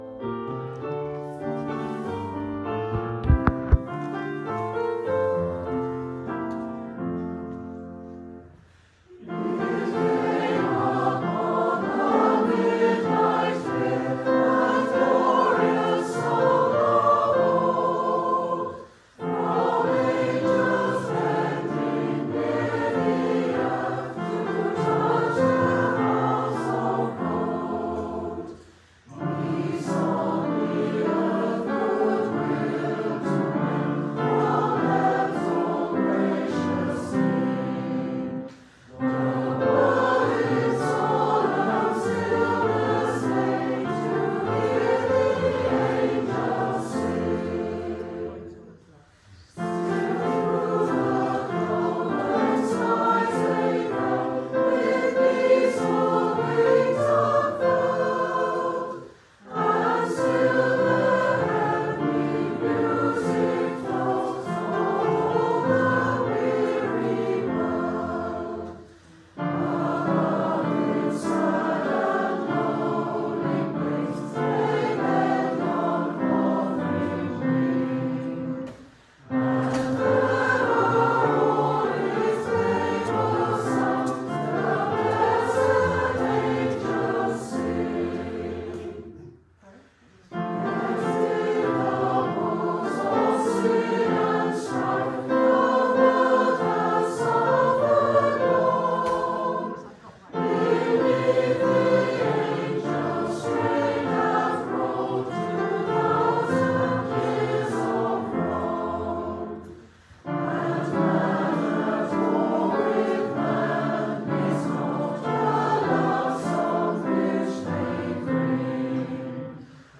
Congregational carols - recordings sung by the choir: